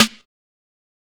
Metro Dry Snare.wav